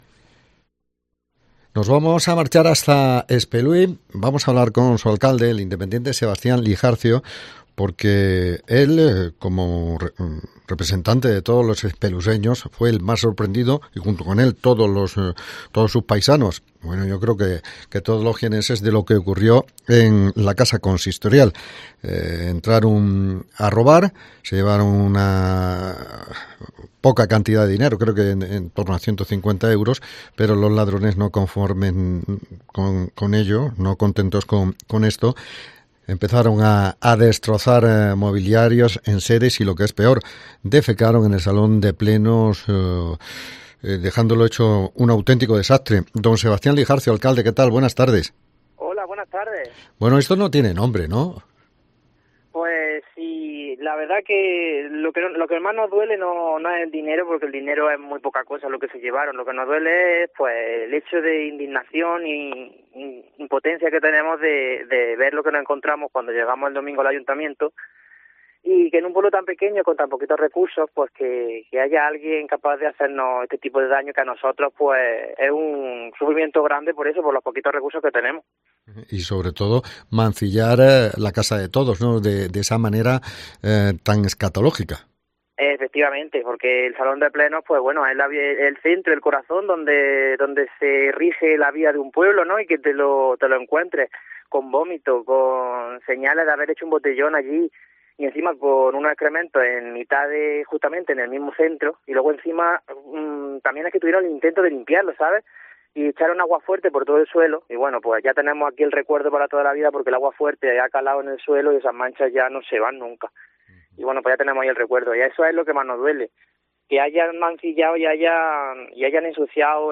Hablamos con el alcalde de Espuley sobre los actos vandálicos